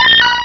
pokeemerald / sound / direct_sound_samples / cries / meowth.aif
-Replaced the Gen. 1 to 3 cries with BW2 rips.
meowth.aif